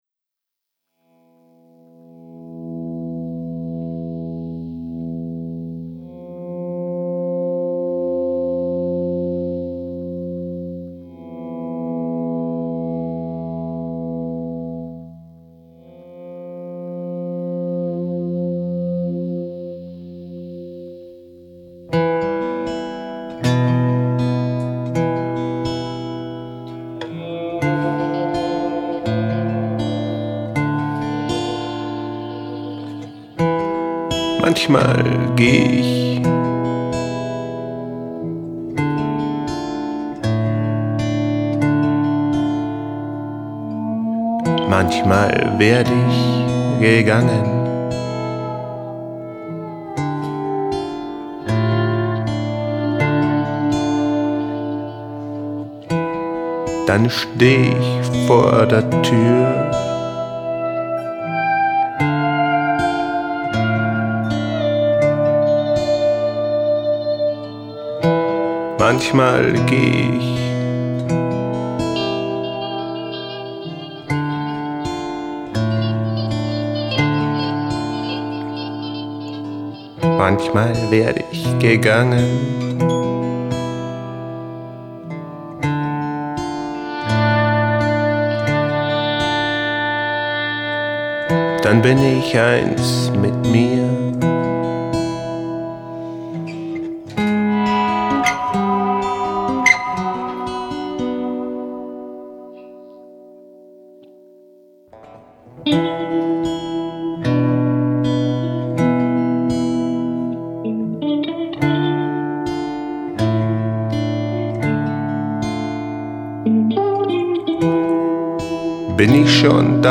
Gesang, elektrische Gitarre, Perkussion, Electronics
elektrische Gitarre, Perkussion, Stepptanz, Electronics